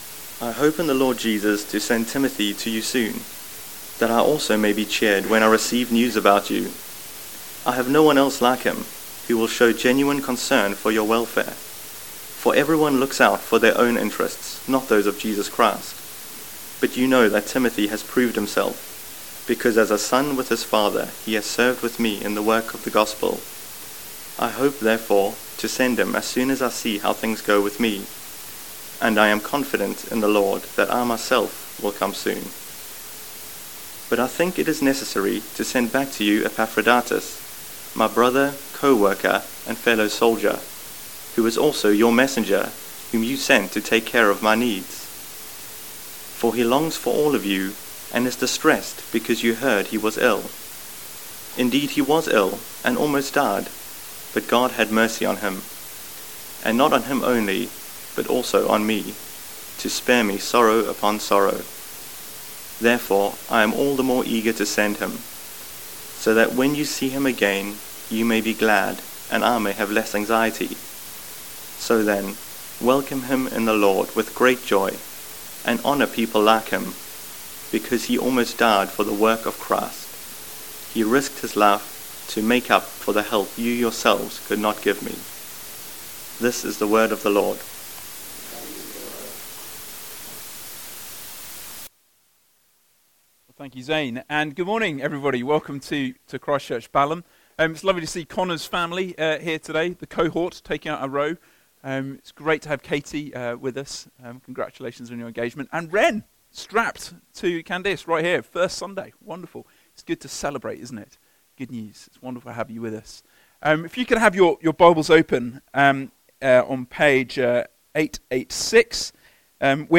This is our sixth sermon in our series on Philippians.